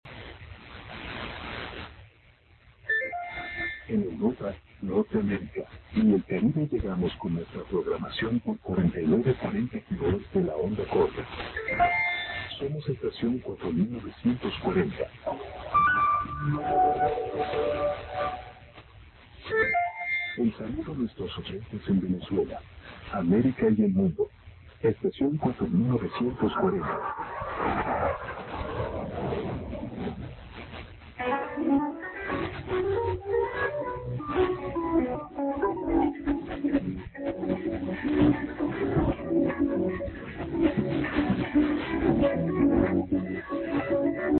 Estaci�n 4940 - Station ID Audio - Venezuela
estacion4940id.mp3